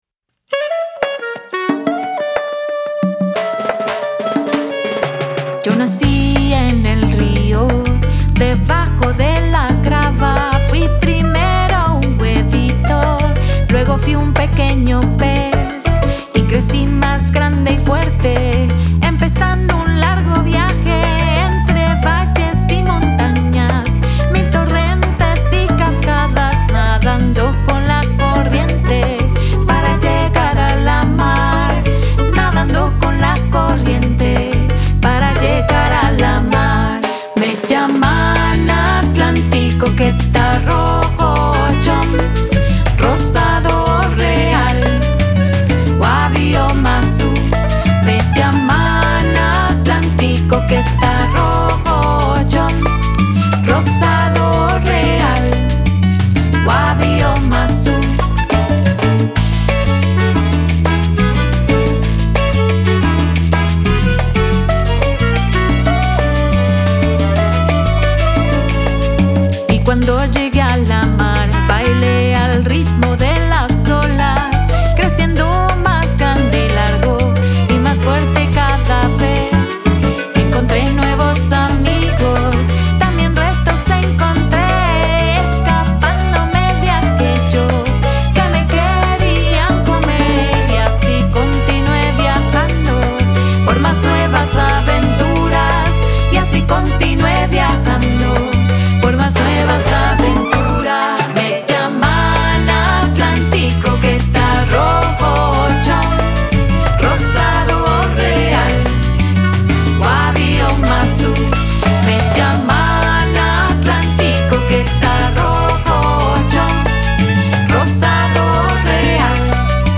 Lead & Backing Vocals
Backing Vocals
Piano
Clarinet
Violins
Percussion & Bass